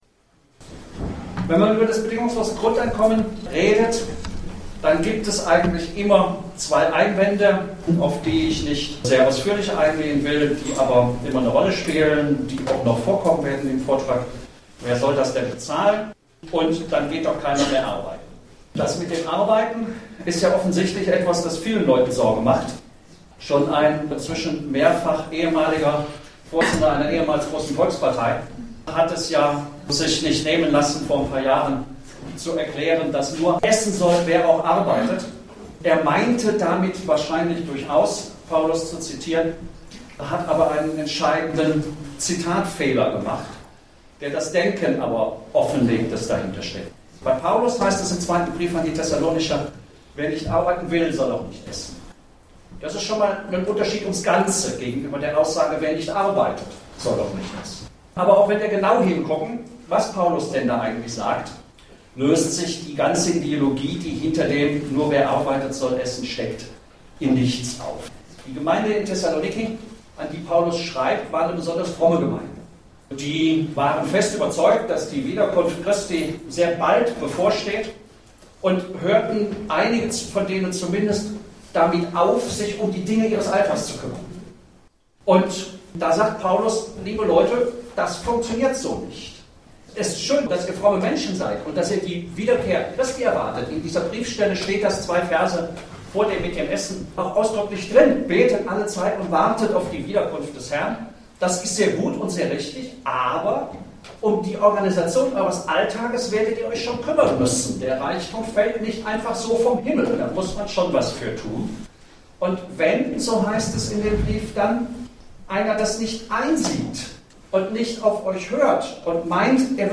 Mitschnitt der Veranstaltung der Attac-Regionalgruppe Kassel vom 7. April 2011
Vortrag